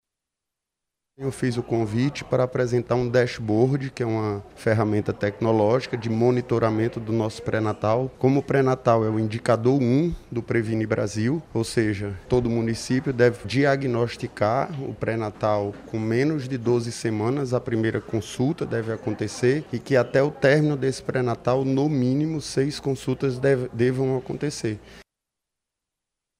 Uma ferramenta desenvolvida pela equipe manaura direcionada a evitar a mortalidade materna. Como explica Djalma Coelho, subsecretário de Gestão da Saúde.
Sonora-Djalma-Coelho-subsecretario-de-Gestao-da-Saude.mp3